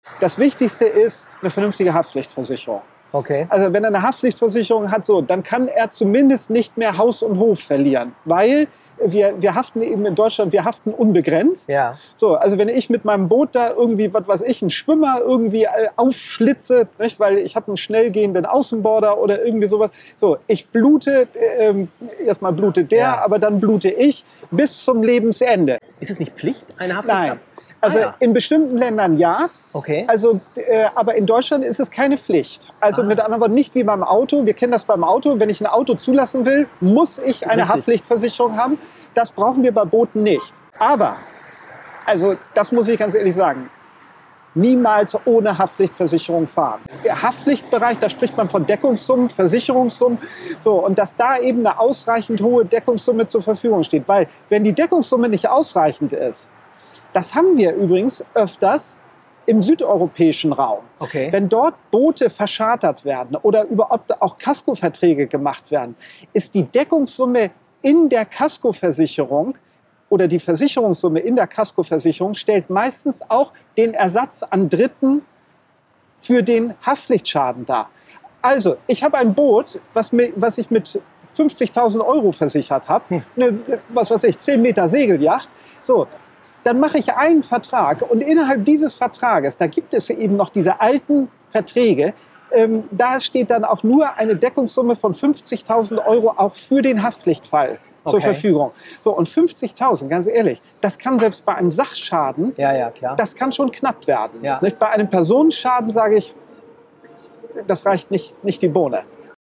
Über die Bootshaftpflicht (Interviewausschnitt)
im Gespräch an den Landungsbrücken